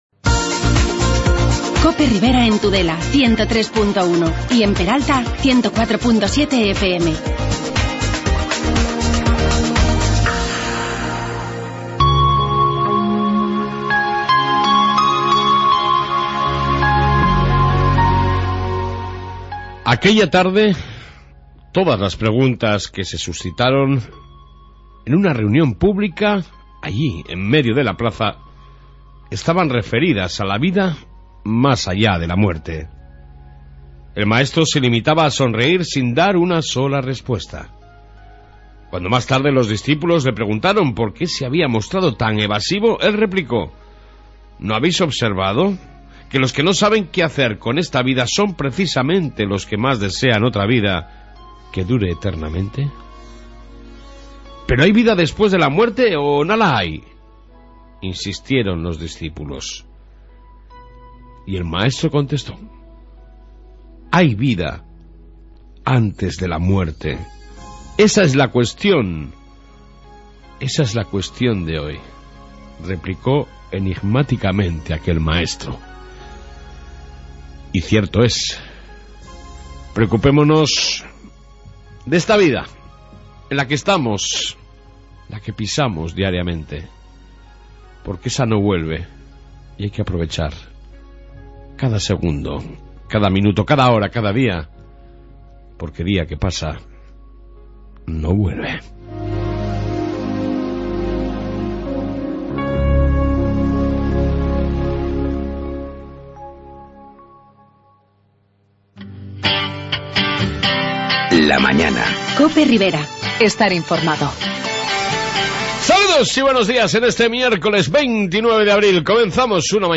Reflexión Matutina y Entrevista